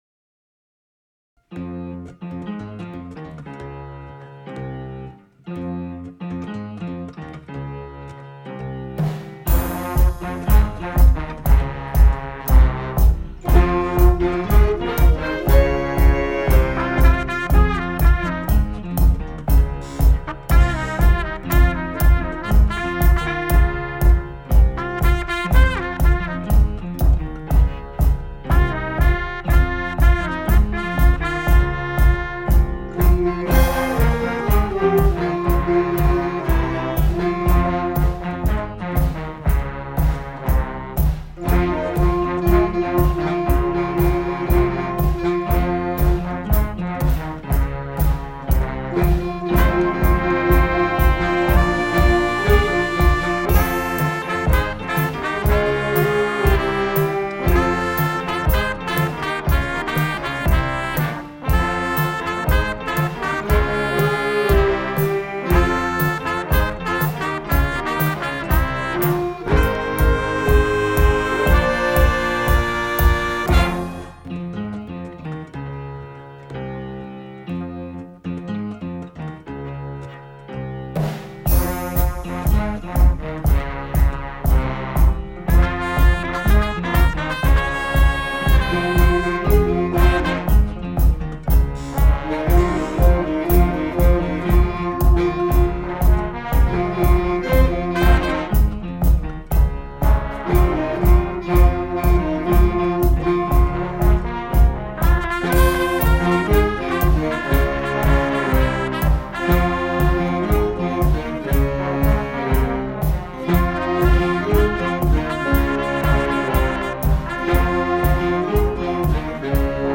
Klavier
Schlagzeug
Bass
Trompete
Alstsax
Tenorsax
Posaune